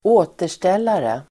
Ladda ner uttalet
återställare substantiv (vardagligt), pick-me-up [informal]Uttal: [²'å:ter_stel:are] Böjningar: återställaren, återställare, återställarnaDefinition: lite alkohol som skall lindra baksmälla (bracer)